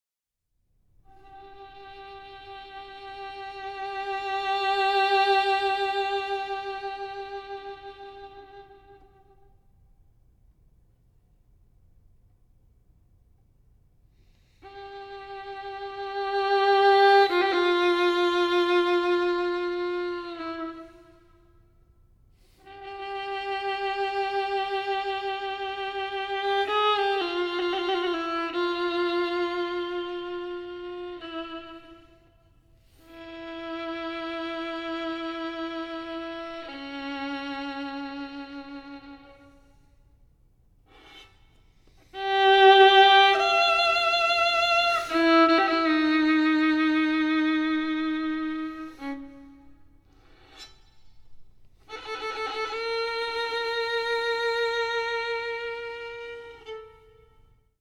(for violin solo)
violin